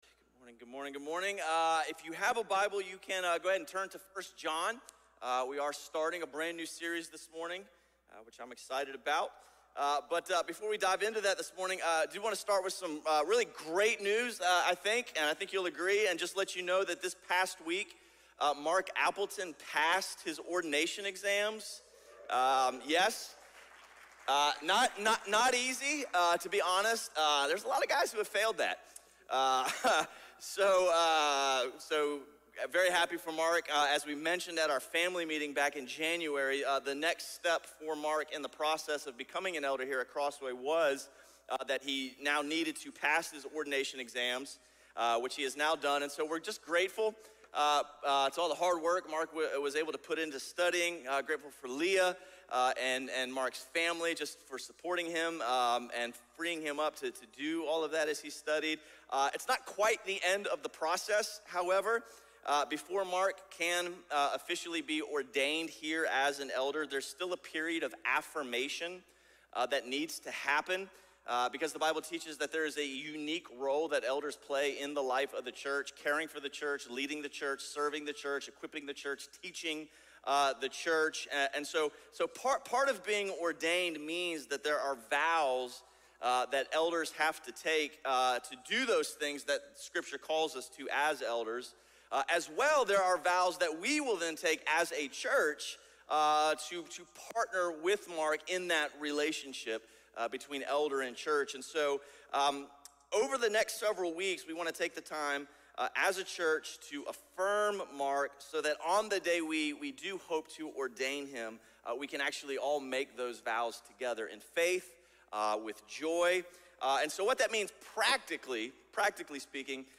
A message from the series "Ekklēsía."